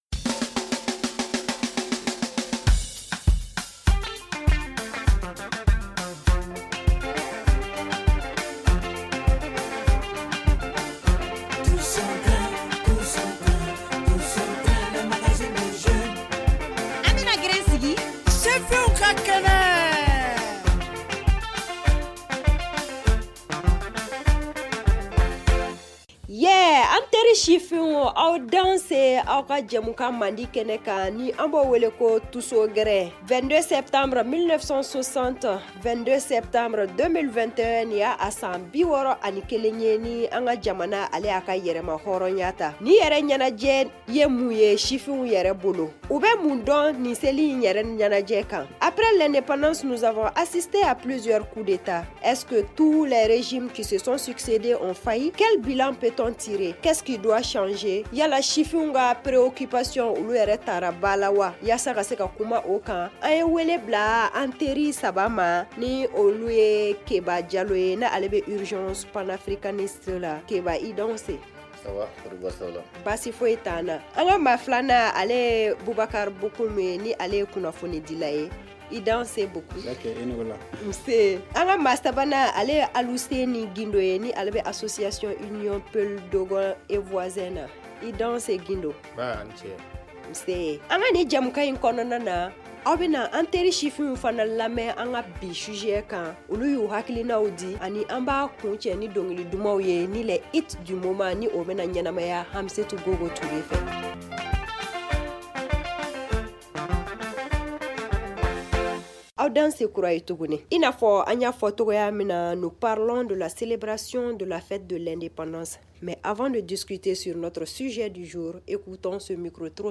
Le tous au grin de cette semaine pose le débat.